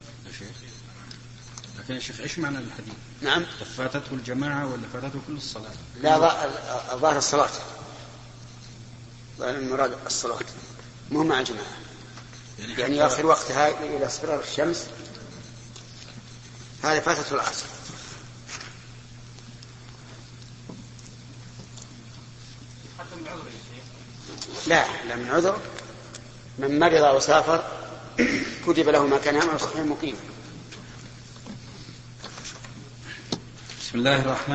📌 الشيـــــخ إبن عثيمين رحمه الله .